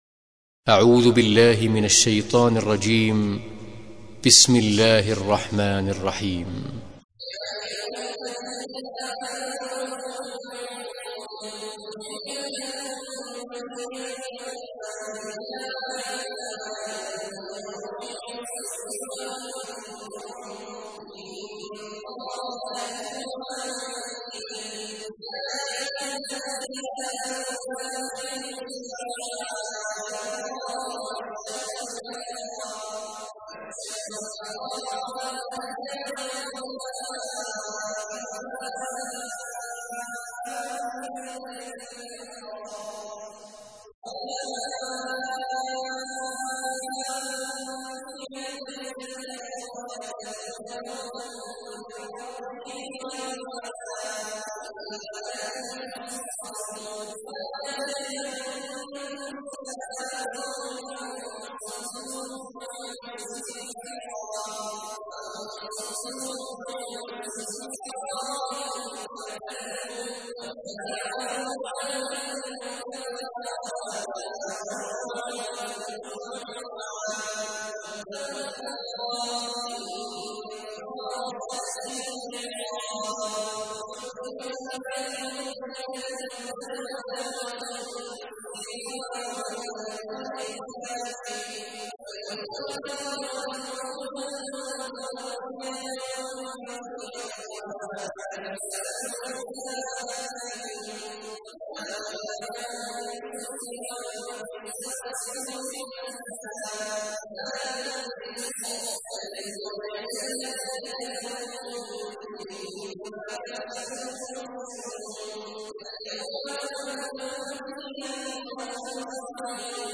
تحميل : 5. سورة المائدة / القارئ عبد الله عواد الجهني / القرآن الكريم / موقع يا حسين